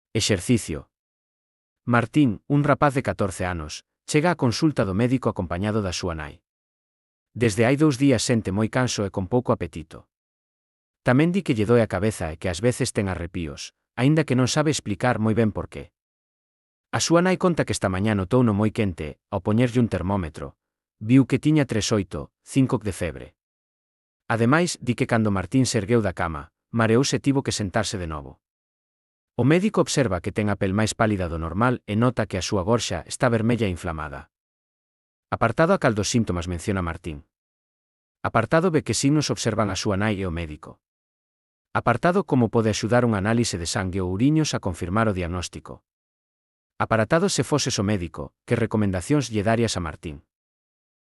Elaboración propia coa ferramenta Narakeet. Transcrición de texto a audio dos exercicios (CC BY-SA)